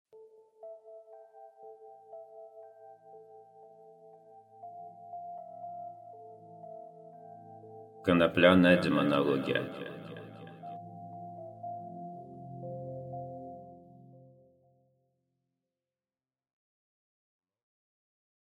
Аудиокнига Молчок | Библиотека аудиокниг